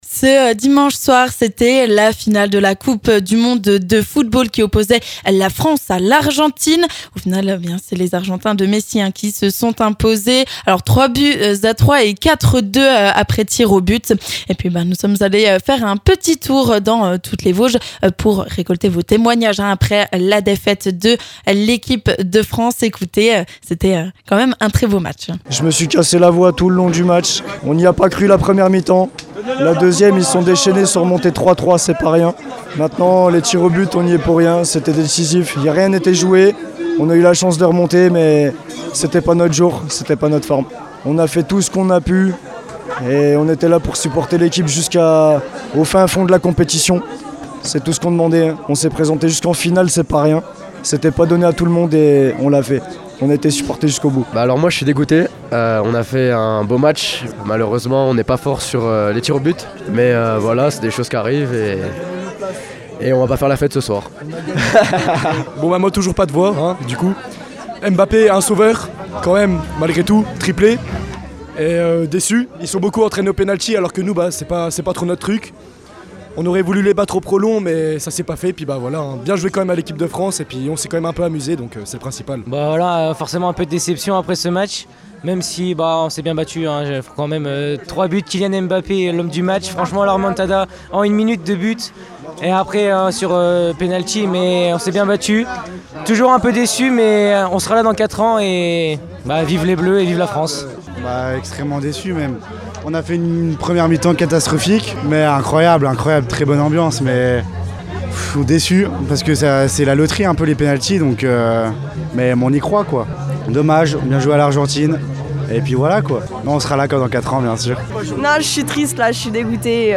Ecoutez les réactions à chaud des supporters vosgiens !